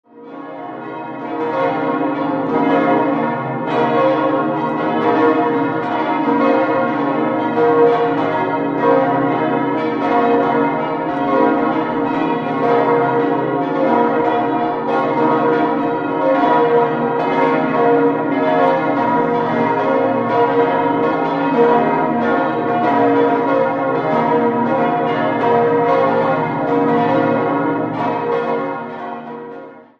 6-stimmiges Gloria-TeDeum-Geläute: h°-cis'-e'-fis'-a'-h'
Heutiges Geläut (seit 1990)